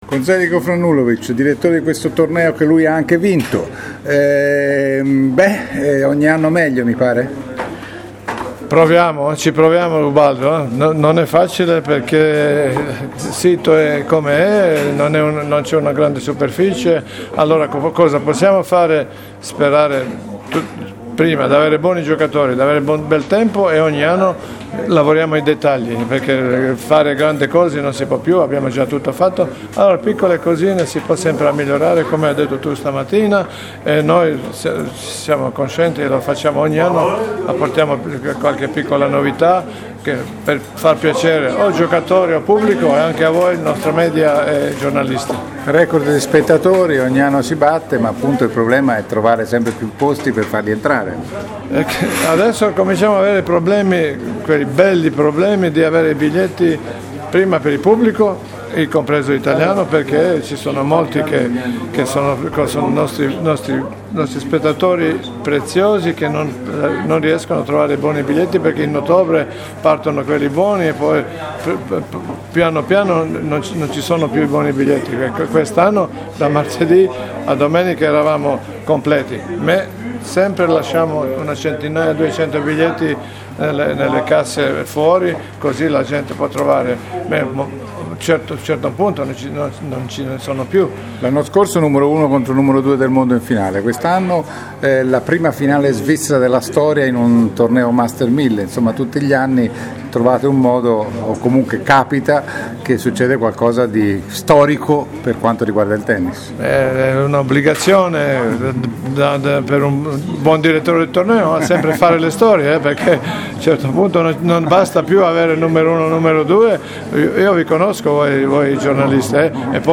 L’intervista esclusiva